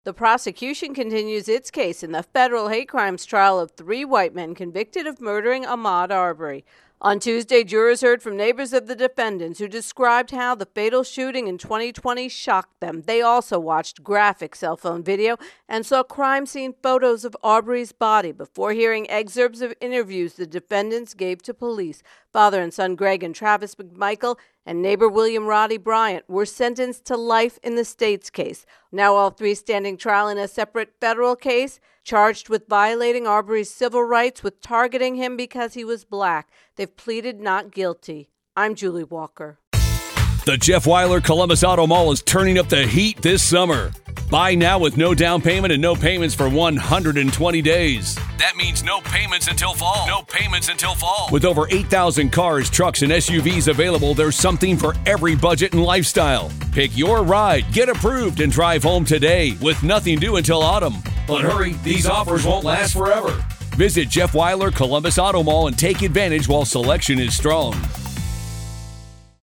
Ahmaud Arbery Hate Crimes intro and voicer